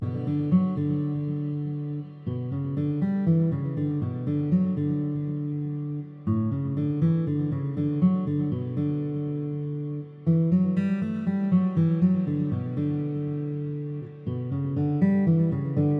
缓慢的原声吉他循环
描述：寒冷的、带有混响的原声吉他循环。
Tag: 120 bpm Rap Loops Guitar Acoustic Loops 2.69 MB wav Key : Bm FL Studio